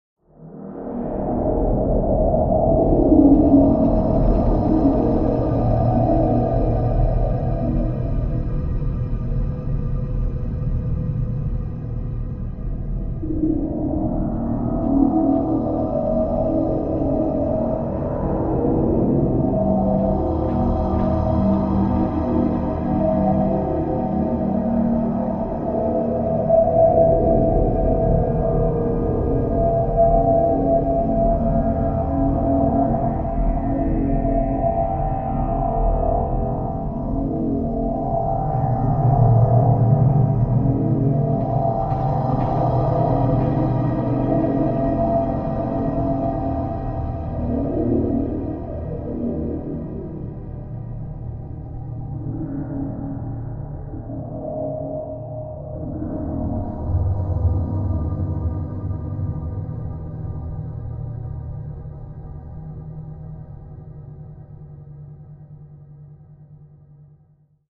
Cave Drone reverberate low pulsing tones